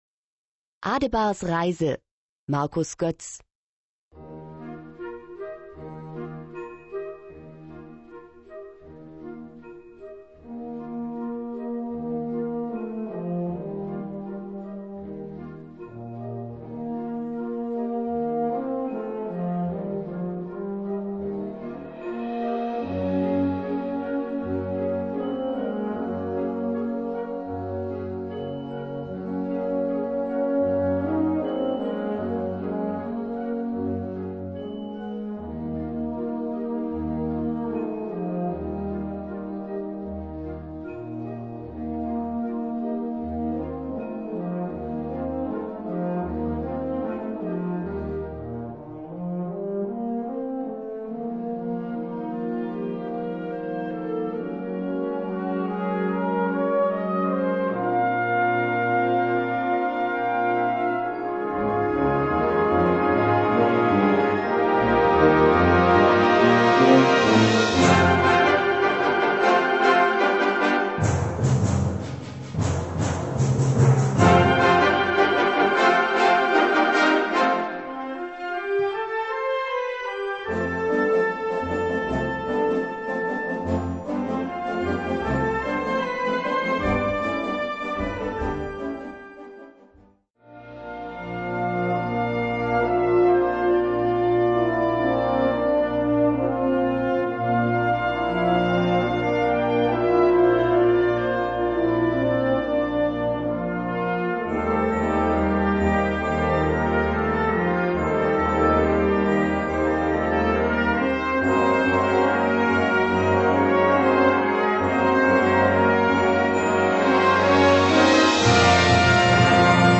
Gattung: Fantasie
Besetzung: Blasorchester